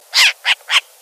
Hibou des marais
Asio flammeus
hibou_marais.mp3